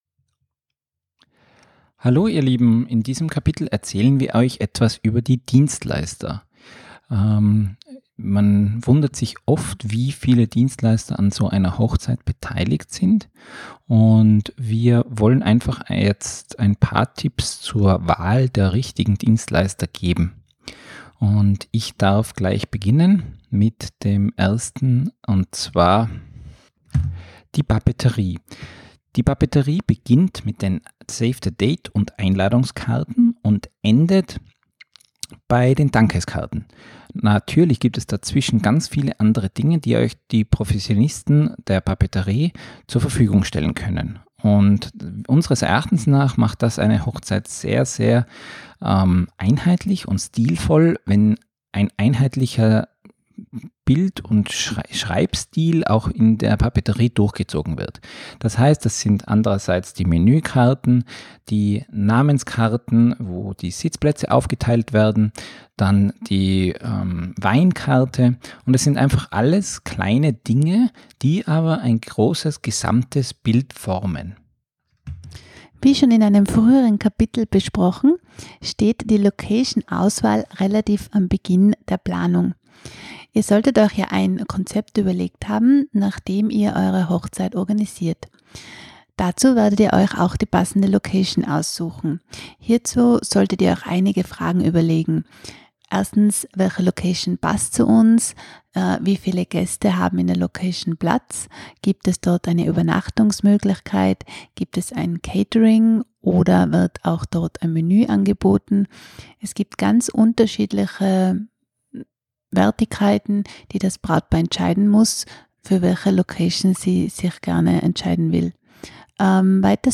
Unser Hörbuch
Deshalb sprechen wir in unserem neuen Hörbuch über die Vorbereitung auf euren großen Tag.